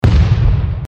hitBone.mp3